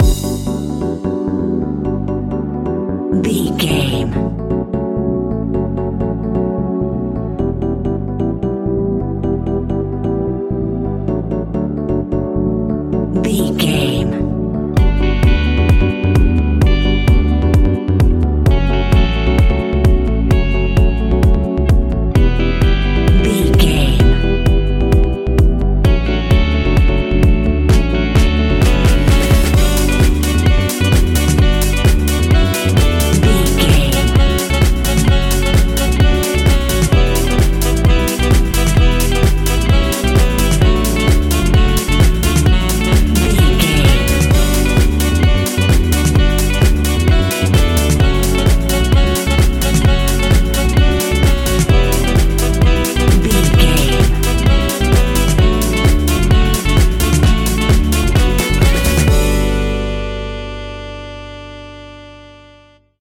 Aeolian/Minor
uplifting
energetic
bouncy
synthesiser
electric piano
bass guitar
strings
saxophone
drum machine
deep house
nu disco
groovy